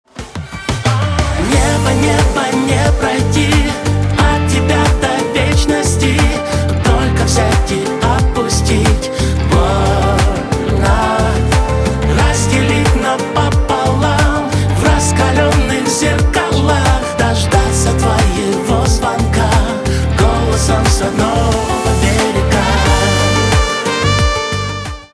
поп
чувственные , битовые , пианино , труба